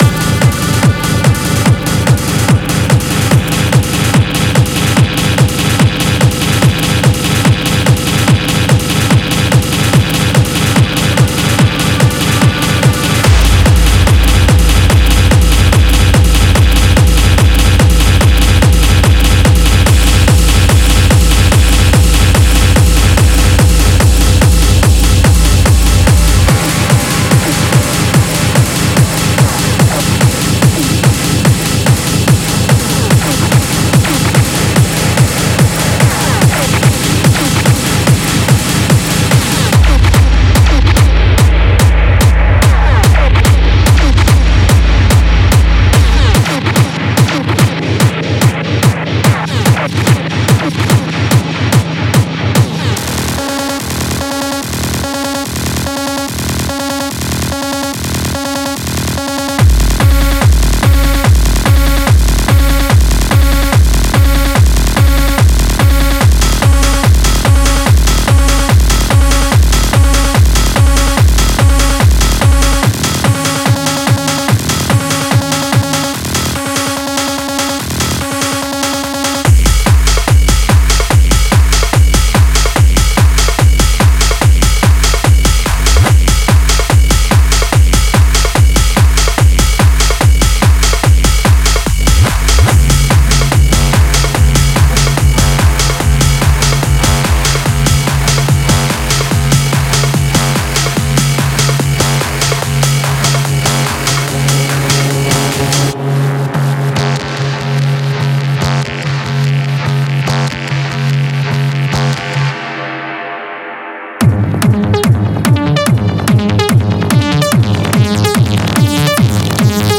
Genre:Techno
デモサウンドはコチラ↓